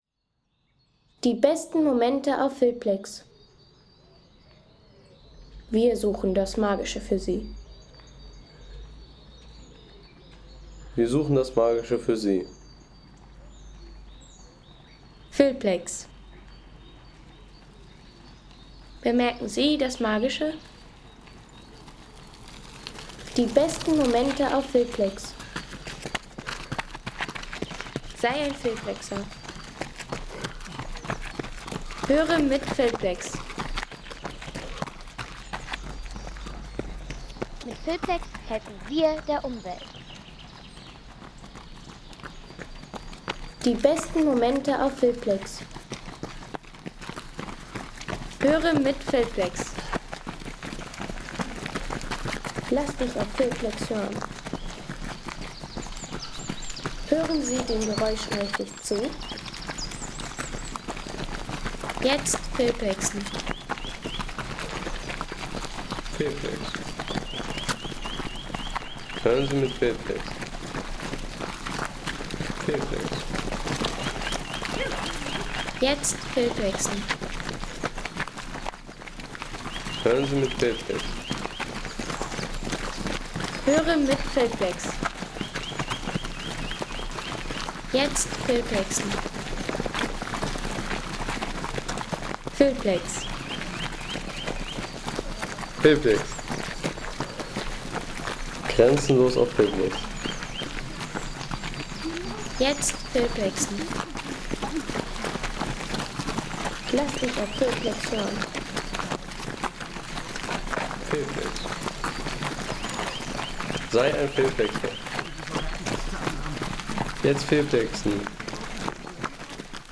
Lauf im Park
Lauf im Park Home Sounds Menschen Laufen Lauf im Park Seien Sie der Erste, der dieses Produkt bewertet Artikelnummer: 171 Kategorien: Menschen - Laufen Lauf im Park Lade Sound.... Lauf im Park – Frühlingserwachen mit über 200 Läufern.